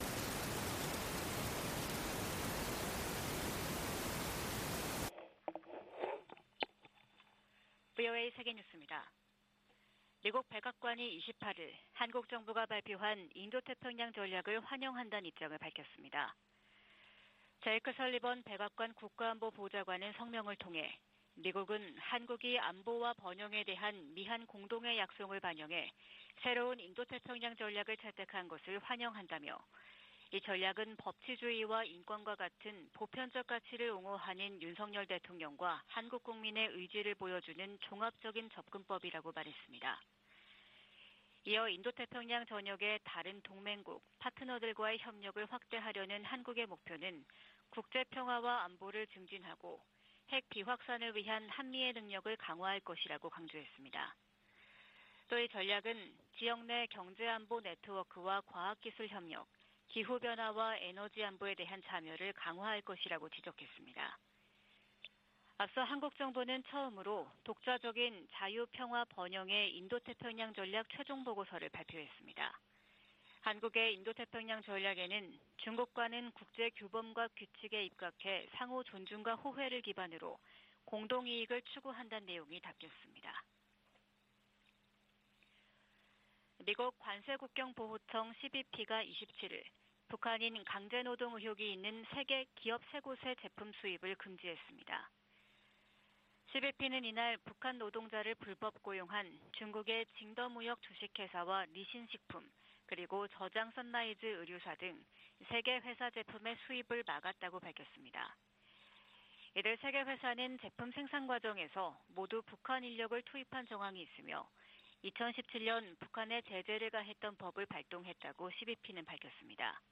VOA 한국어 '출발 뉴스 쇼', 2022년 12월 29일 방송입니다. 윤석열 한국 대통령은 북한의 드론 즉 무인기 도발을 계기로 강경 대응 의지를 연일 강조하고 있습니다. 유엔은 북한 무인기가 한국 영공을 침범한 것과 관련해 한반도의 긴장 고조에 대해 우려하고 있다는 기존 입장을 재확인했습니다.